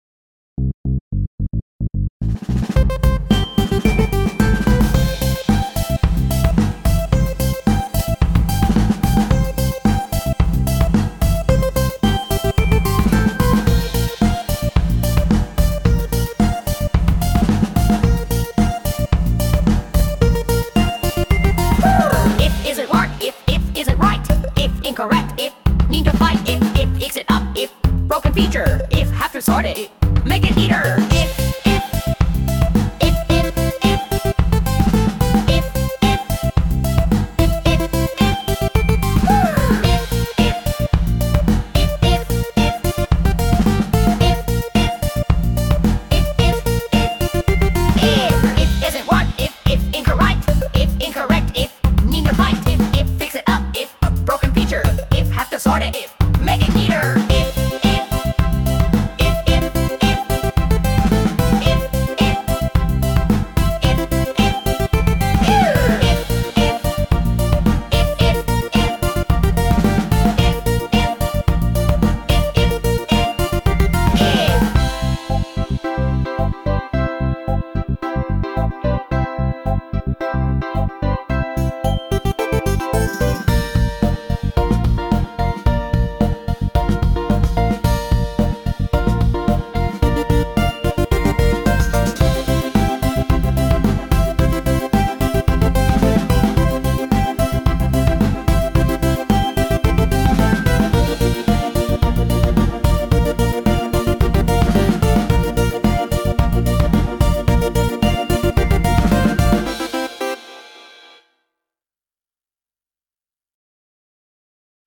Sound Imported : Transindental Kettle Boiling
Sung by Suno